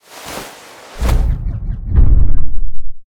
Sfx_tool_hoverpad_unpark_right_01.ogg